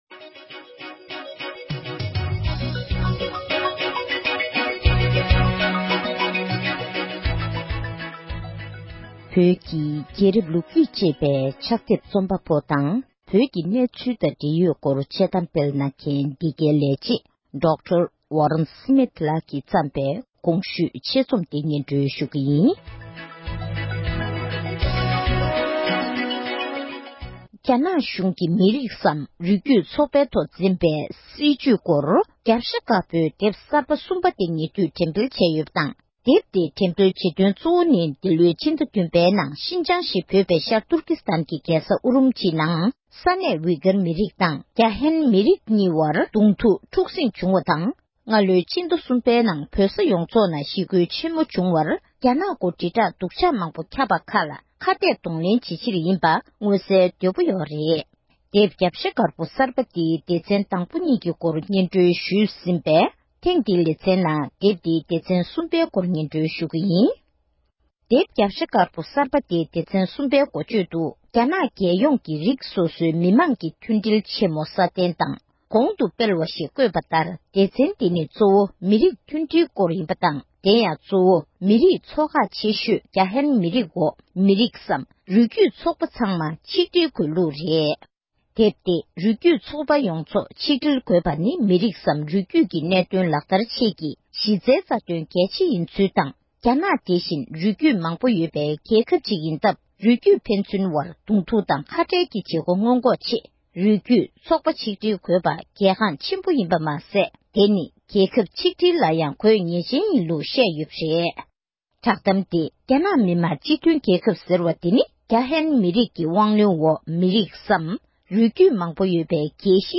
ལགས་ཀྱིས་བོད་སྐད་ཐོག་ཕབ་བསྒྱུར་གྱིས་སྙན་སྒྲོན་ཞུས་པར་གསན་རོགས༎